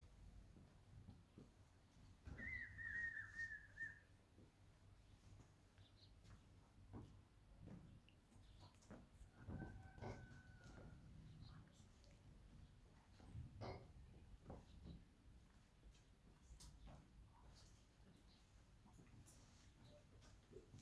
Spot-backed Puffbird (Nystalus maculatus)
Location or protected area: Ruta 40 entre San Carlos y Angastaco
Condition: Wild
Certainty: Photographed, Recorded vocal